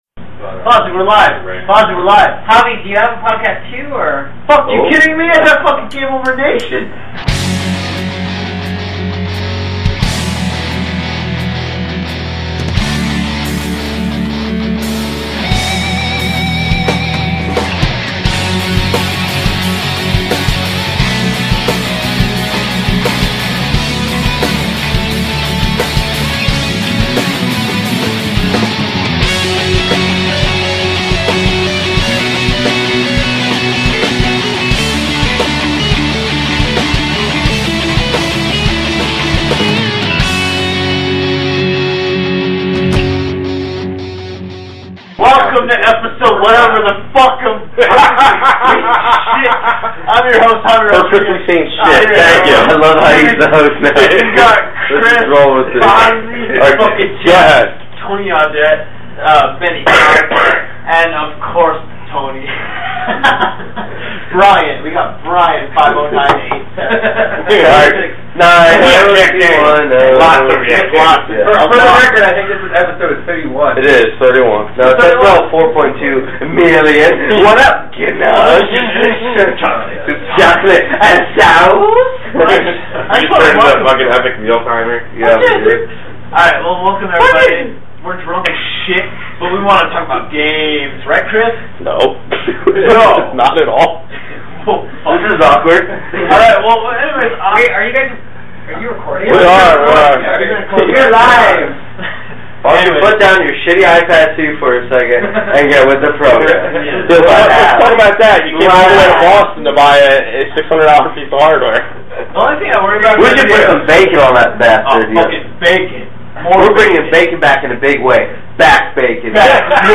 On this classic episode we’re recording from a hotel room at PAX East 2011 with a bunch of our podcast and IGN friends. We had some major audio issues so this is the most salvagable of the party.
This episode, like all our classic episodes, appears unedited except for some music we don’t have (and never had) the license too (and Spotify makes us edit out now).